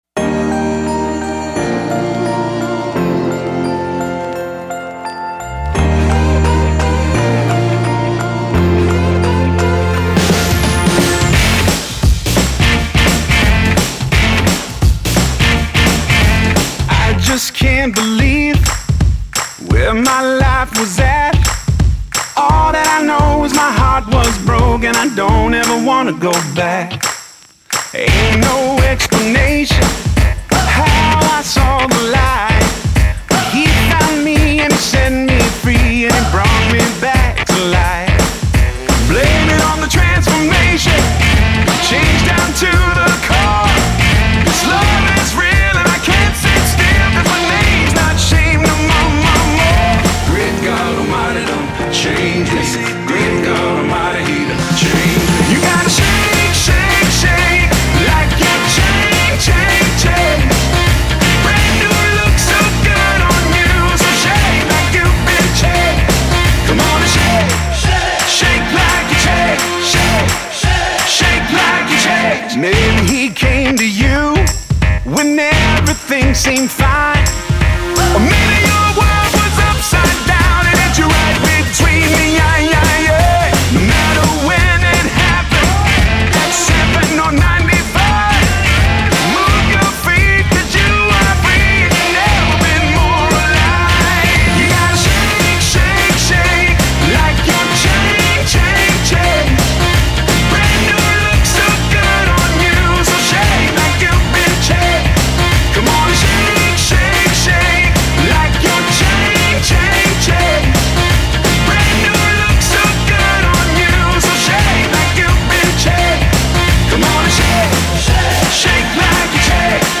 318 просмотров 260 прослушиваний 29 скачиваний BPM: 172